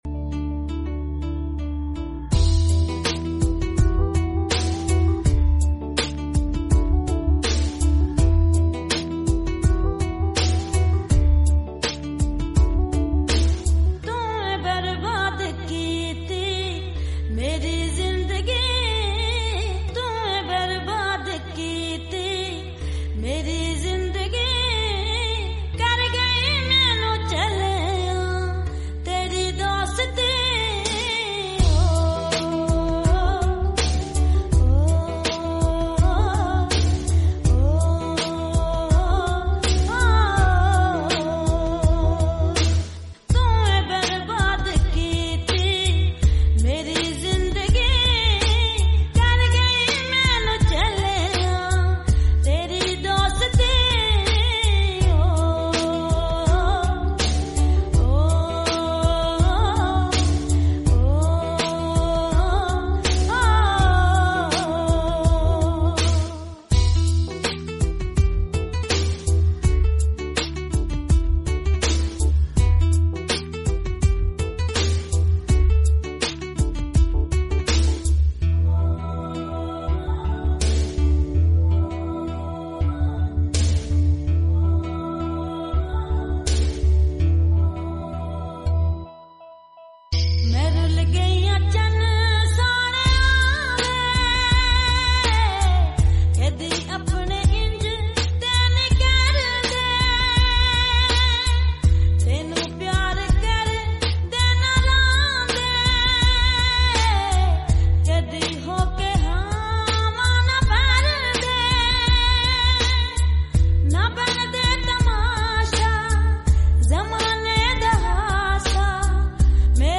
full sad song